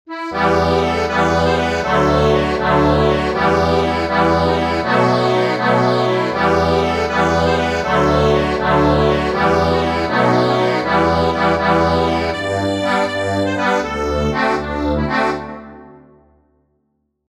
Instrument: accordion